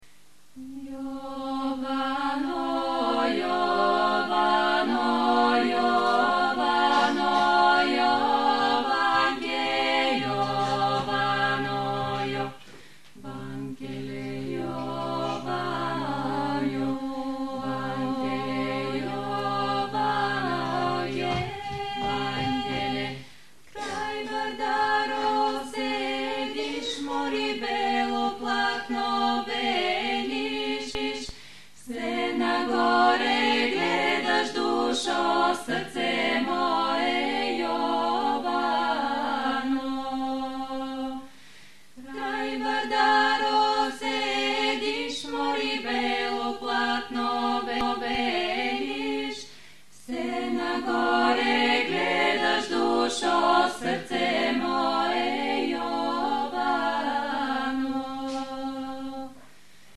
This Bulgarian folklore formation was found in 2005 and became popular by the means of their numerous concerts and appearings on TV.